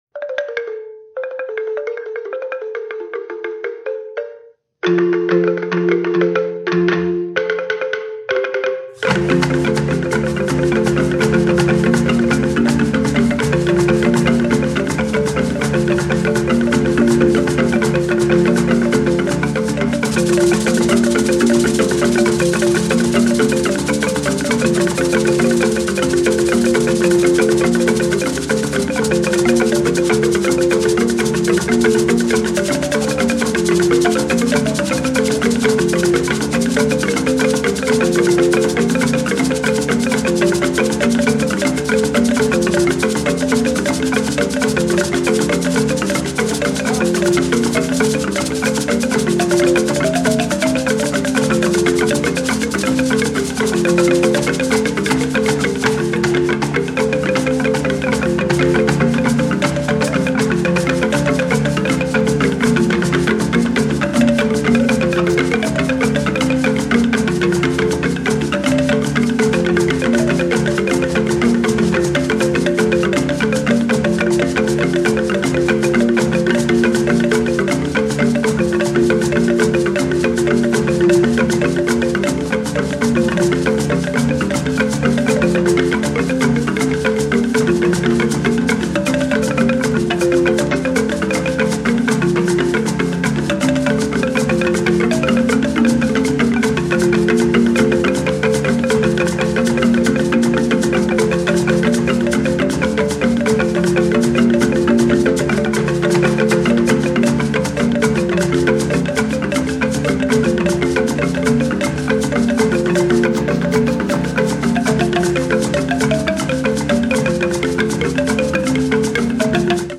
最高！！60,70年代のアフリカのフィールド録音集！プリミティブな格好良さをモダンな音像で捉えた大傑作！